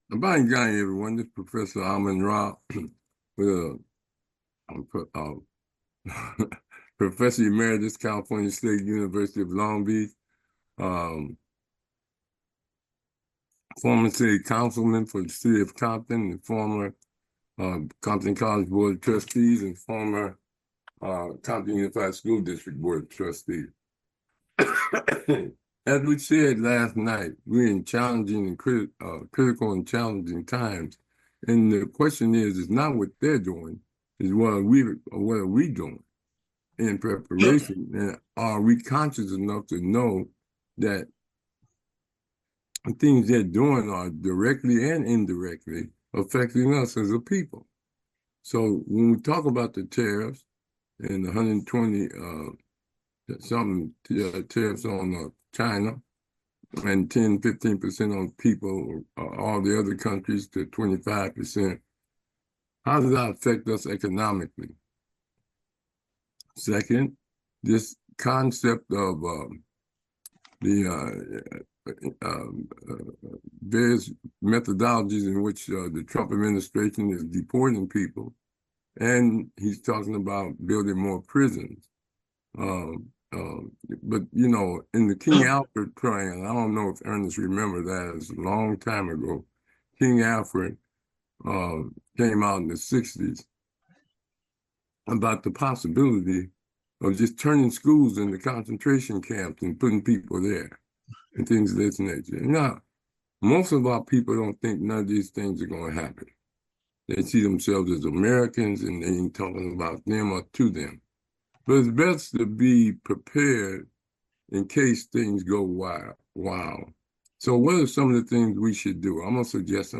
Lecture Series